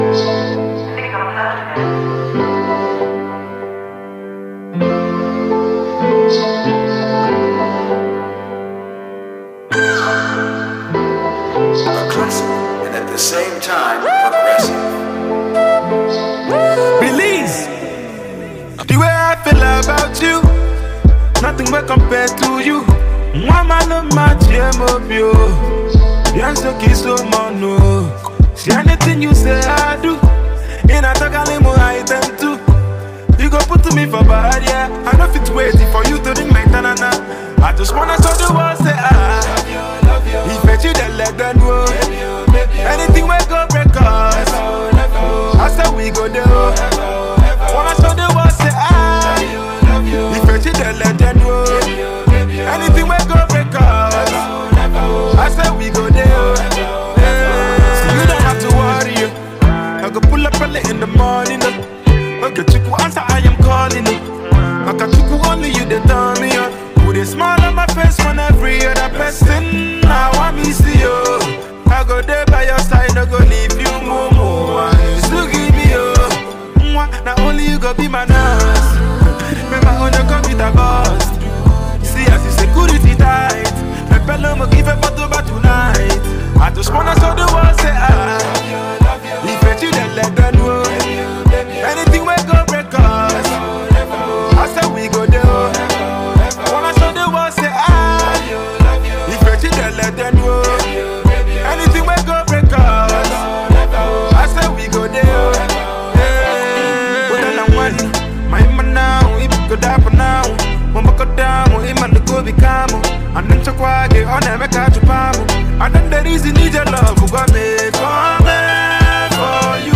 Nigerian Indigenous rapper